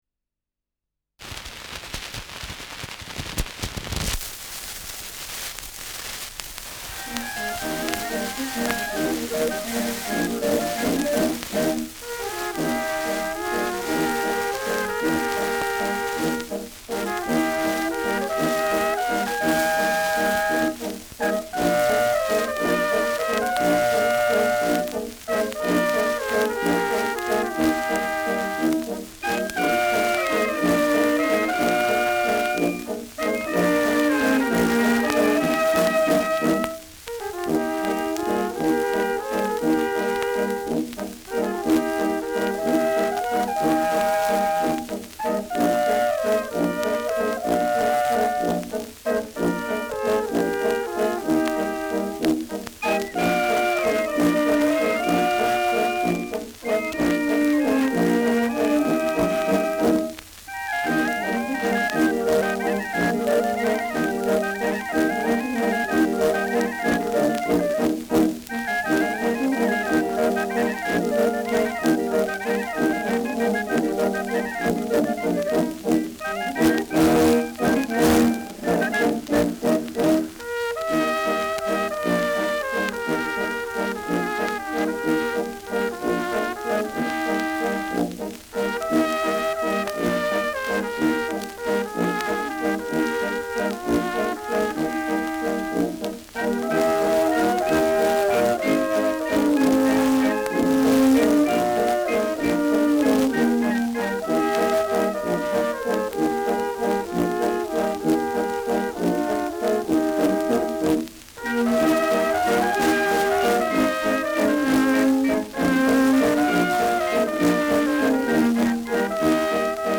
Schellackplatte
Rauschen präsent